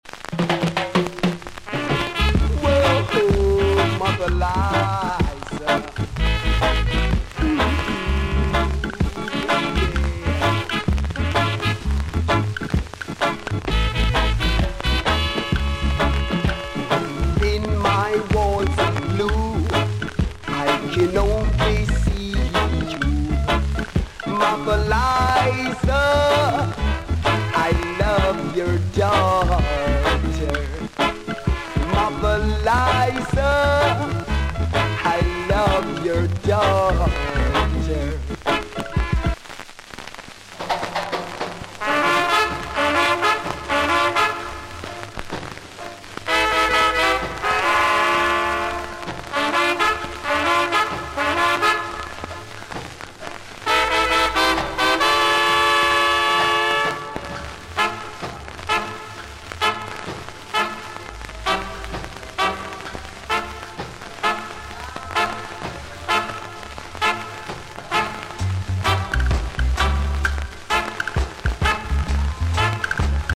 Notes: broke/plays with noise